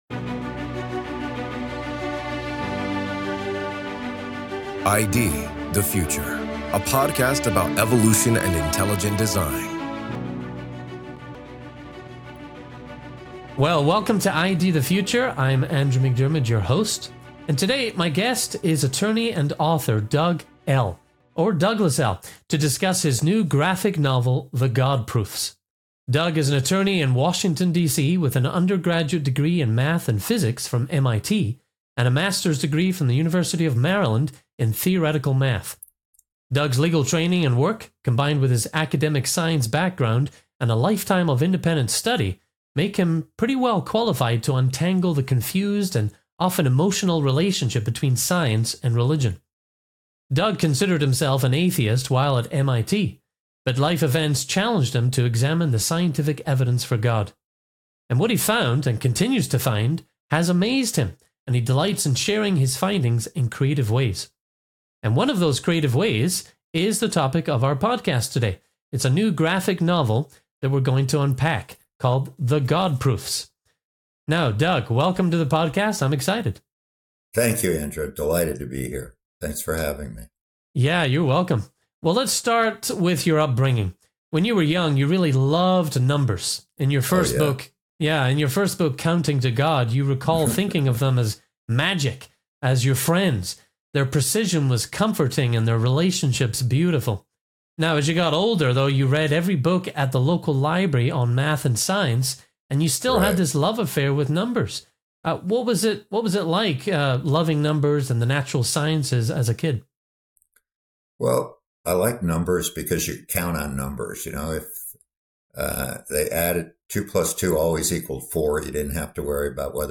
The God Proofs: An Interview